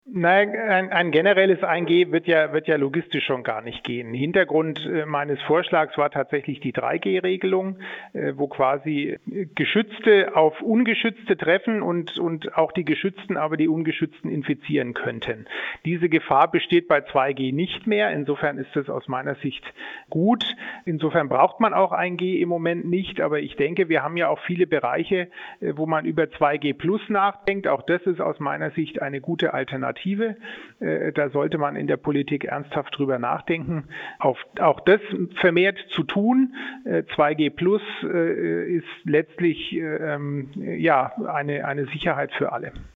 Impfkapazität, Boostern und 3G-Kontrollen: Schweinfurts Ordnungsreferent Jan von Lackum im Interview - PRIMATON